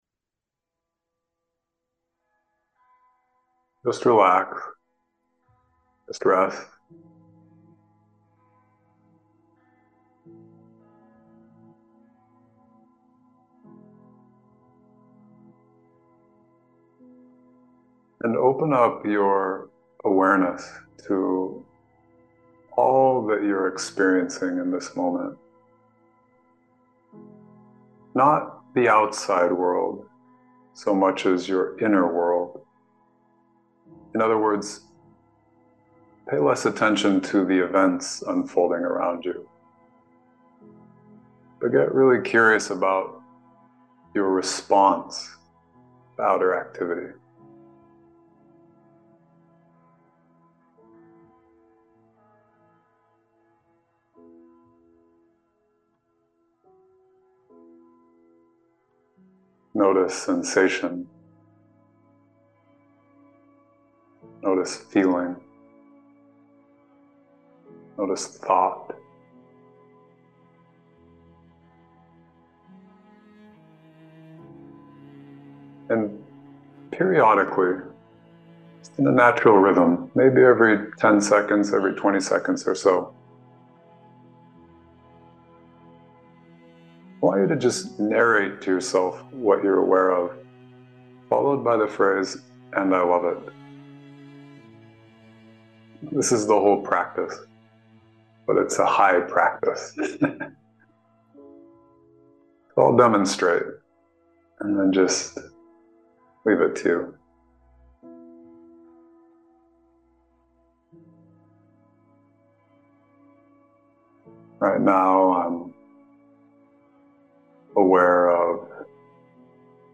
Come What May & Love It Meditation
Come-What-May-and-Love-It-Meditation-1.mp3